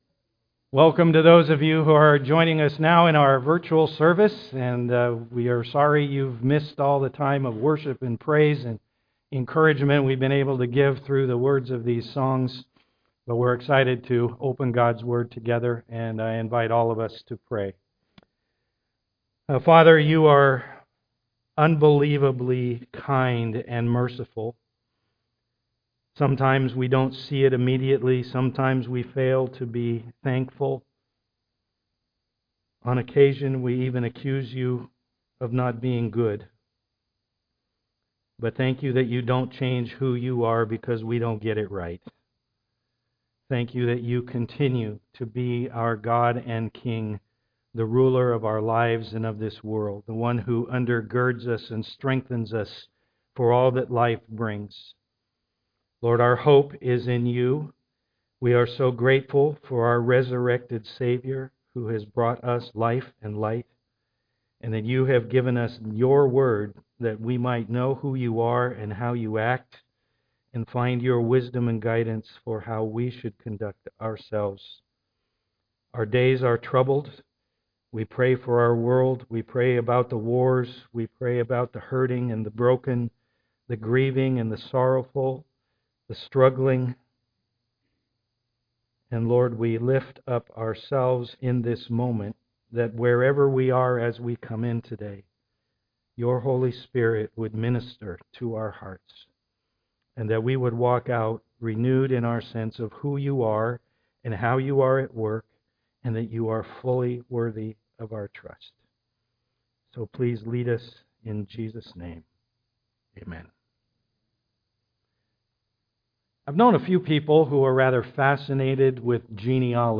Service Type: am worship